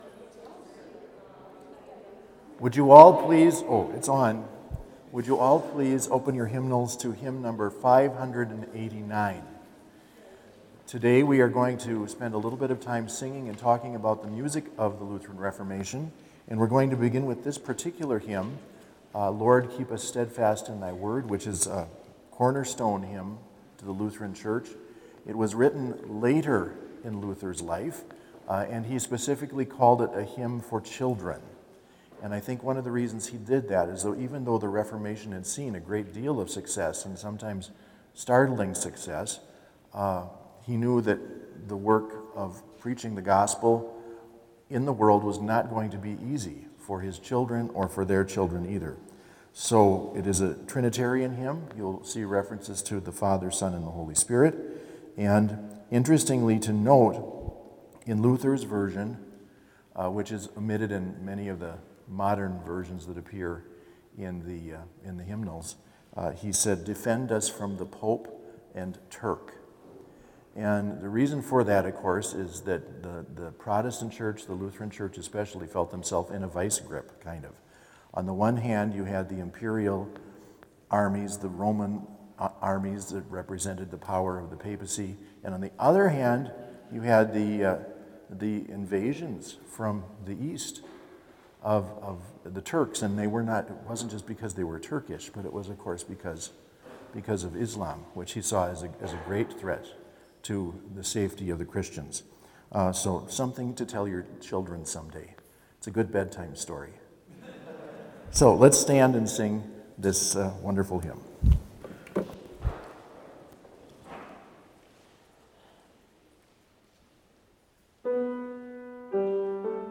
Complete service audio for Chapel - October 29, 2020
Hymn 589 - Lord, Keep Us Steadfast in Thy Word